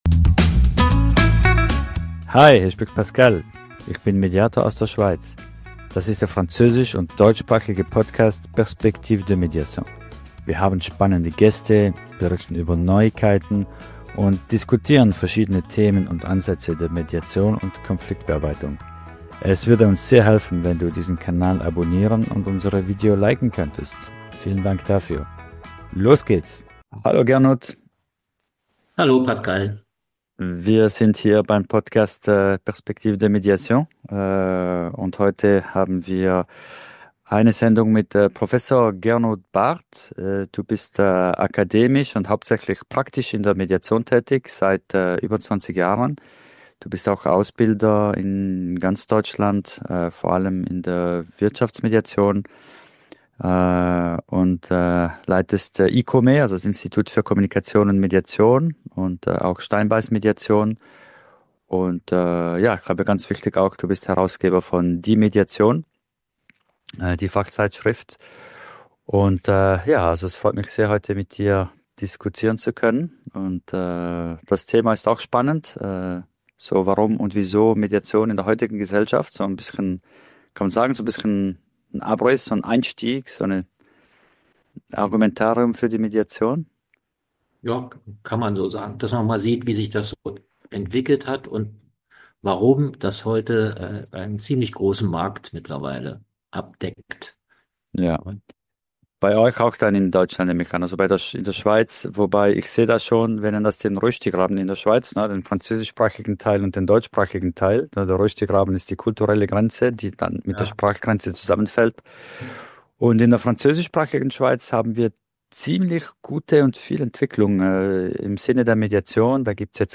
Ein inspirierendes Gespräch über Herausforderungen und Chancen – für alle, die den gesellschaftlichen Wandel nicht nur beobachten, sondern aktiv mitgestalten möchten.